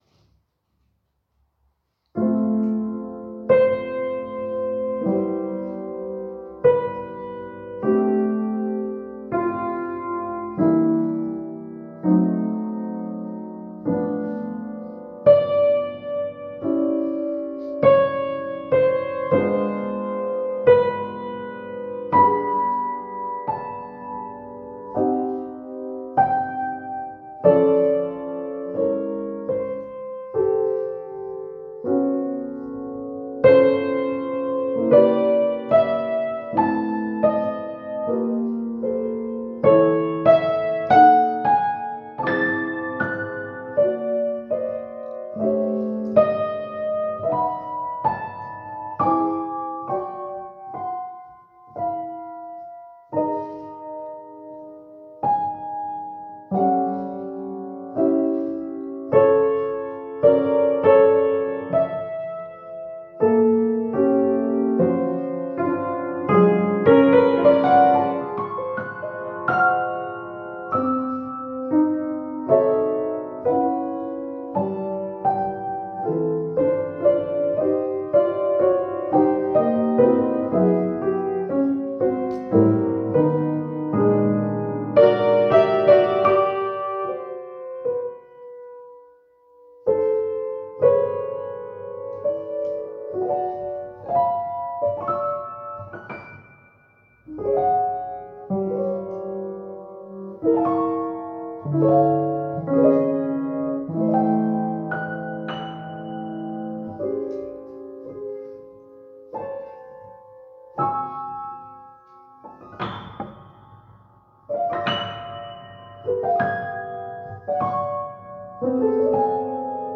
Disonance No. 2 - Piano Music, Solo Keyboard - Young Composers Music Forum